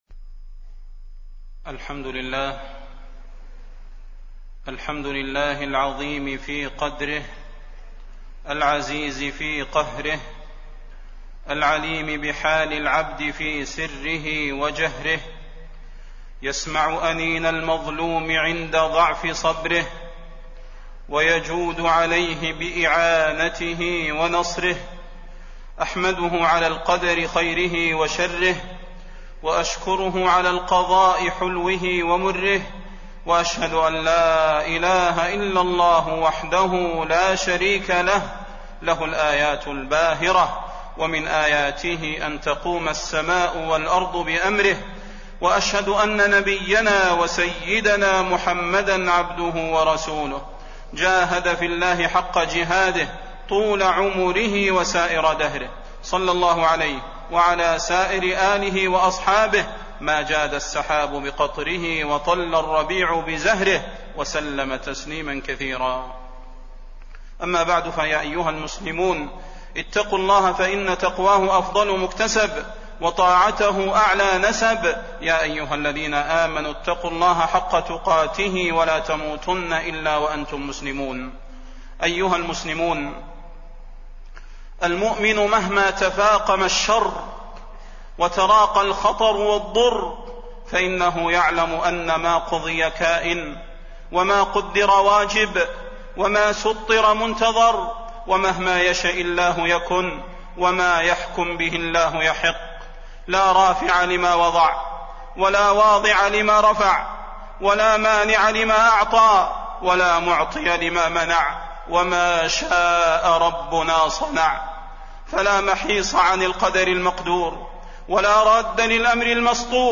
تاريخ النشر ١ ربيع الأول ١٤٣٢ هـ المكان: المسجد النبوي الشيخ: فضيلة الشيخ د. صلاح بن محمد البدير فضيلة الشيخ د. صلاح بن محمد البدير صحوة الأمة بالتمسك بالكتاب والسنة